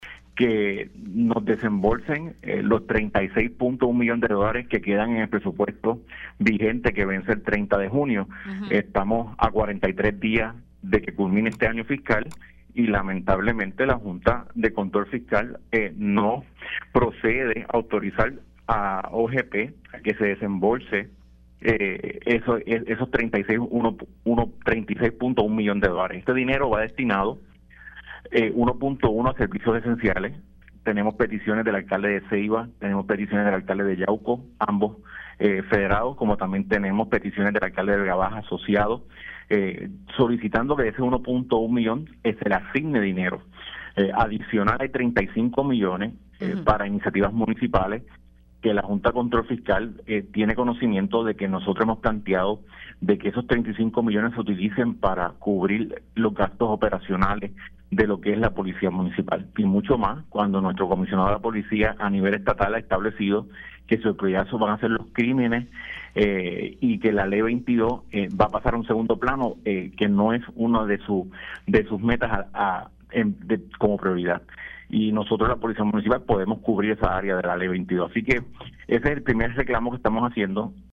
119-GABRIEL-HERNANDEZ-ALC-CAMUY-Y-PRES-FED-ALCALDES-PIDEN-DESEMBOLSO-DE-VARIOS-MILLONES-PARA-MUNICIPIOS.mp3